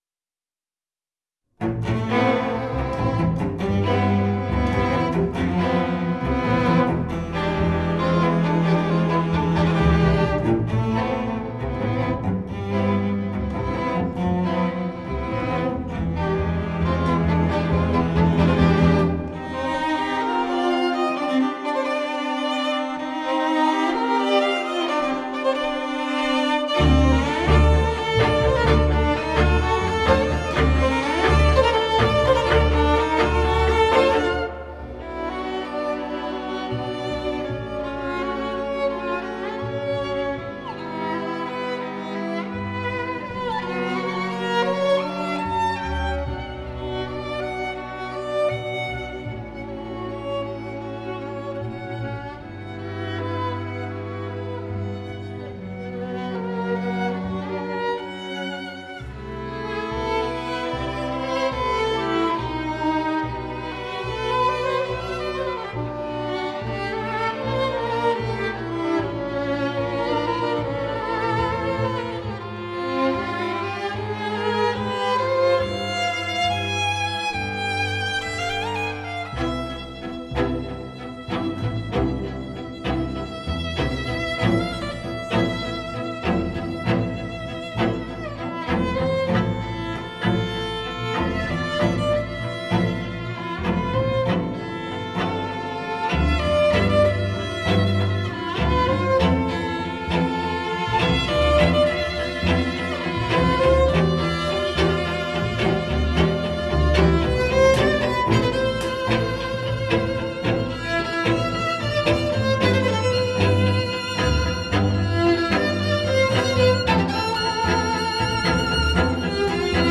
Organico: voce recitante e quintetto d’archi